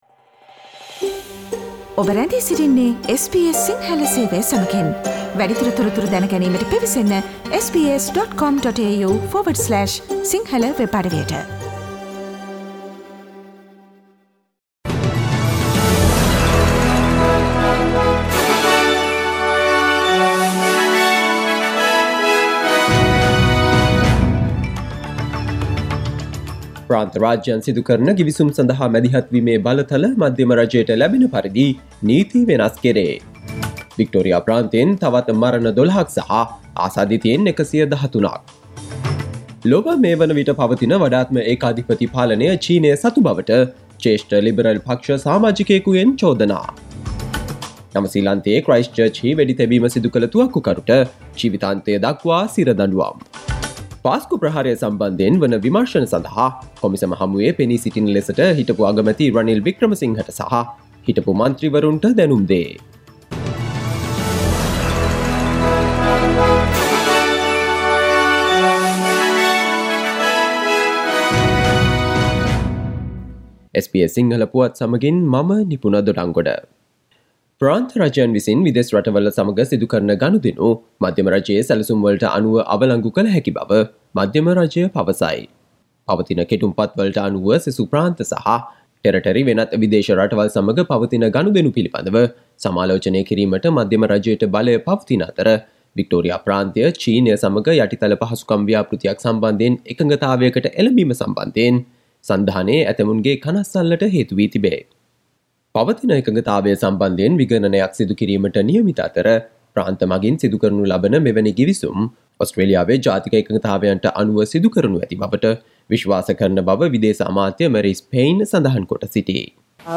Daily News bulletin of SBS Sinhala Service: Friday 28 August 2020
Today’s news bulletin of SBS Sinhala radio – Friday 28 August 2020.